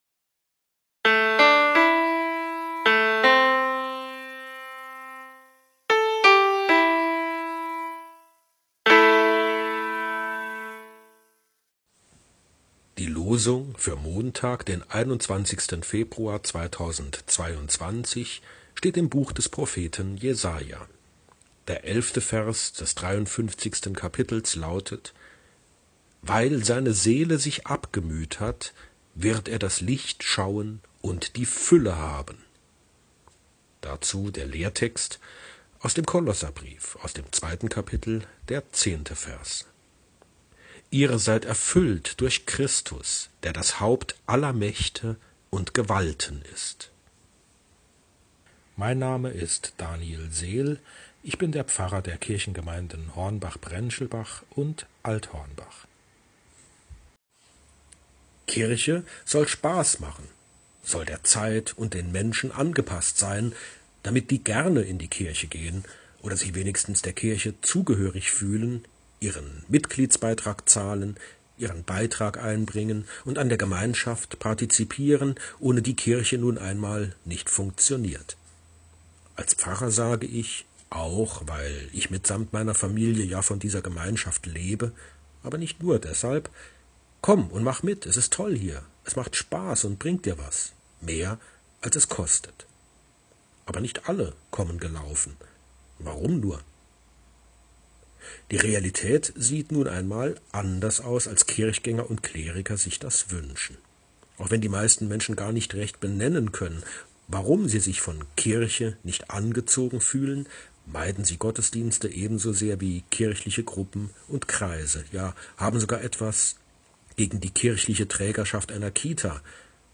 Losungsandacht für Montag, 21.02.2022